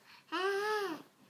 aaah.mp3